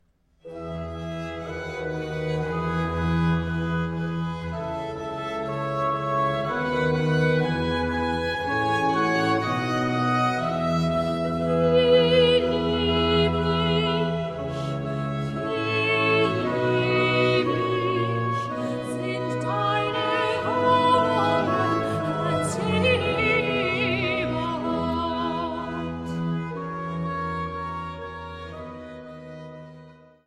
Kirchweihkantaten
Sopran
Orgel und Leitung